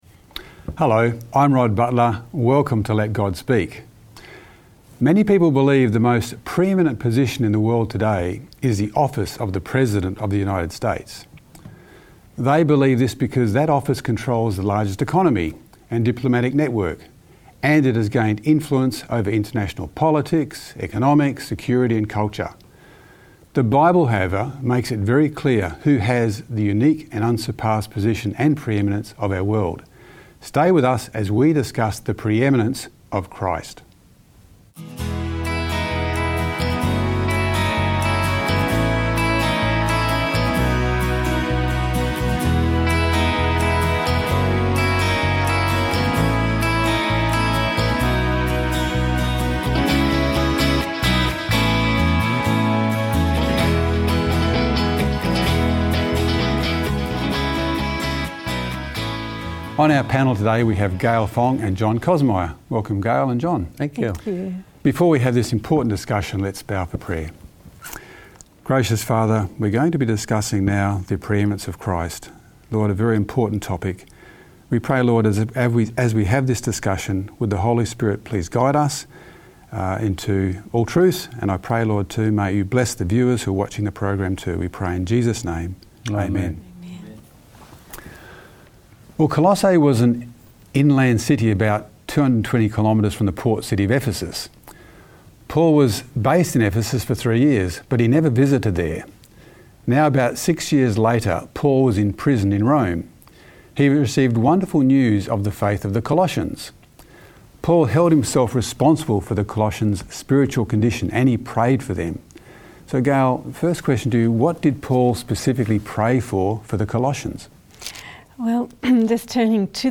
The Bible, however, makes very clear who holds the most preeminent position in the world today. Stay with us as we discuss the preeminence of Christ.